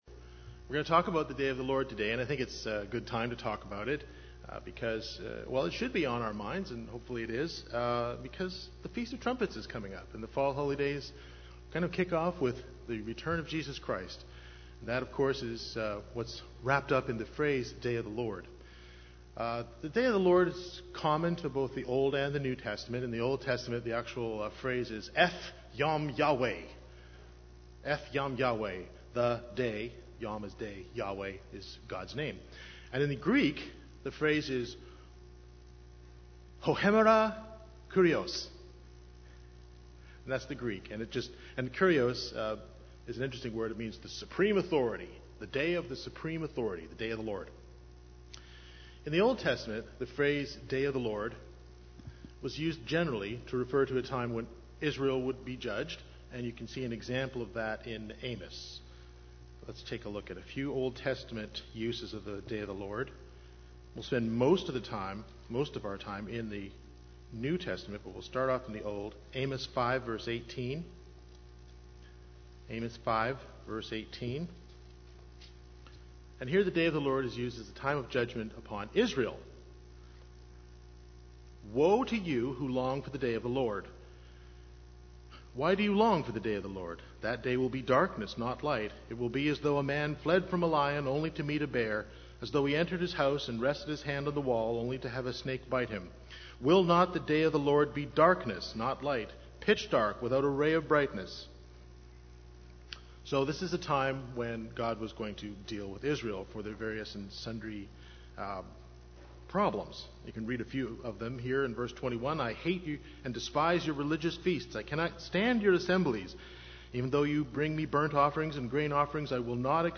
The sermon explores the three unique words used in Scripture to describe Jesus' return also known as the Day of the Lord. The words are: Parousia (Jesus' personal hands-on involvement), Epiphaneia (the brightness and visibility of His presence), Alpokalypis (revealing the spiritual reality of God's work among mankind both present and future).